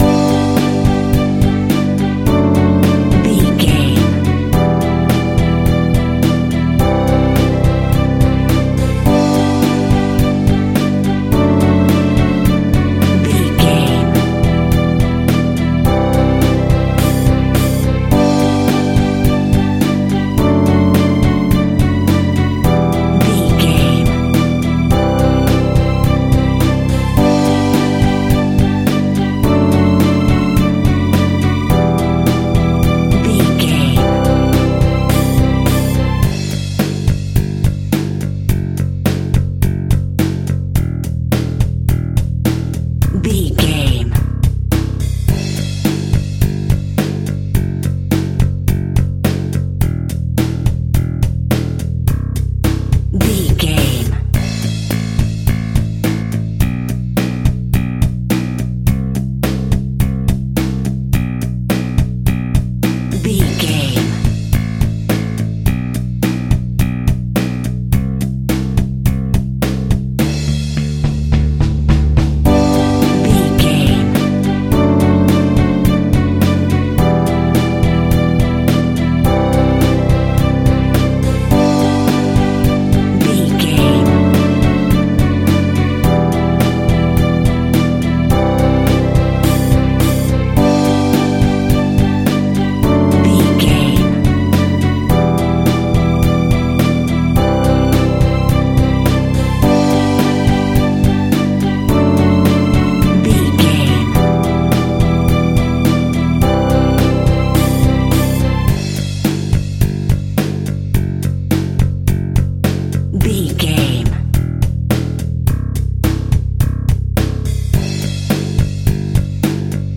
Ionian/Major
pop rock
indie pop
fun
energetic
uplifting
drums
bass guitar
piano
hammond organ
synth